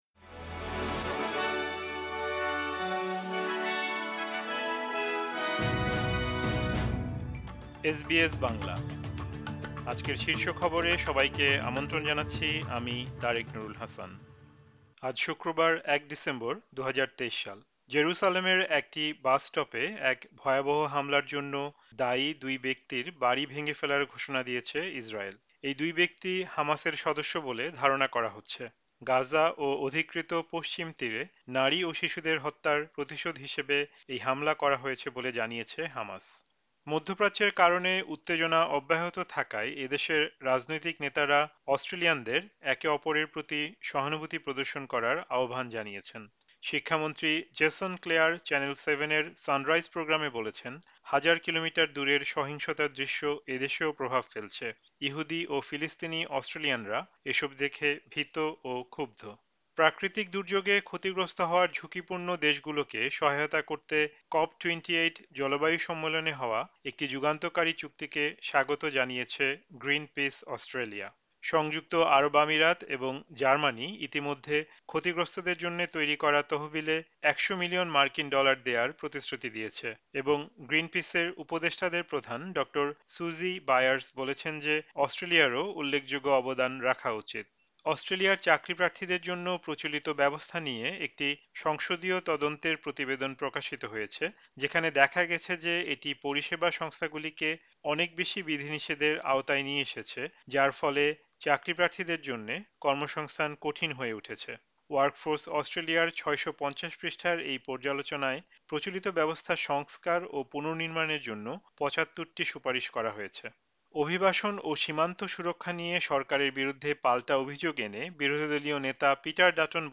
এসবিএস বাংলা শীর্ষ খবর: ১ ডিসেম্বর, ২০২৩